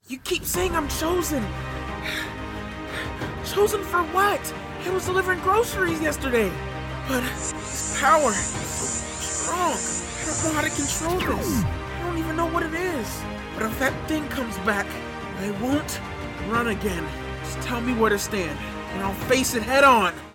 Teen
Young Adult
Character Voice